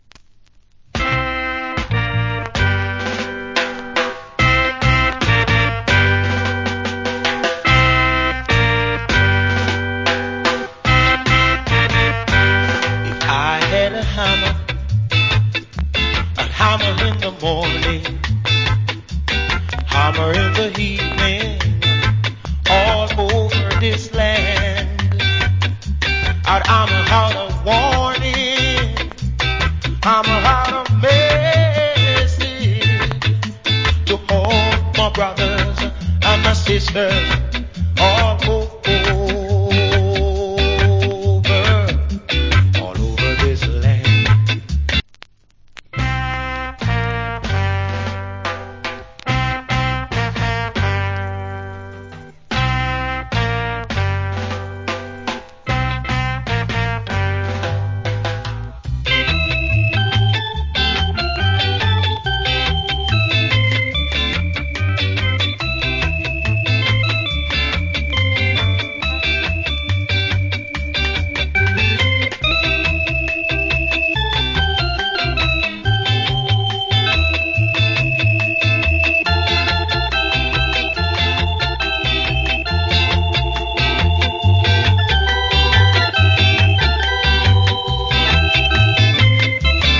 Boss UK Skinhead Reggae Vocal.